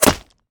bullet_impact_rock_01.wav